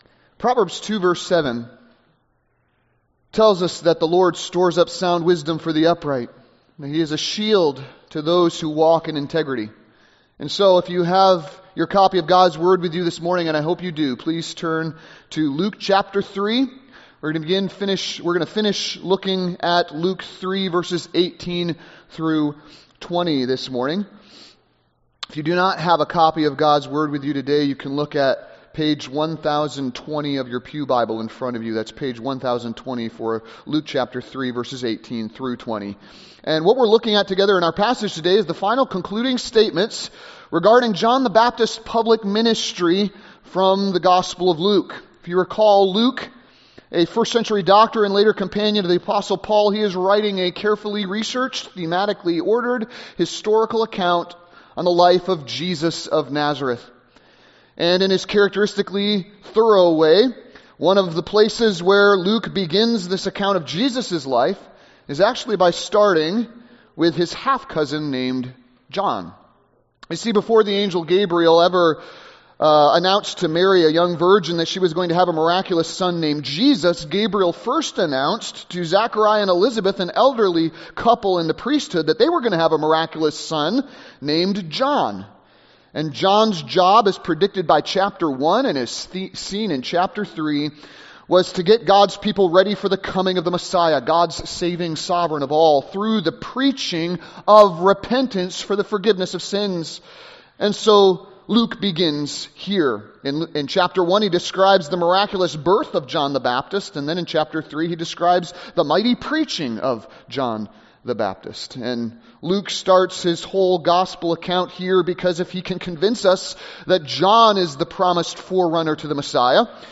Sermons | Grace Chapel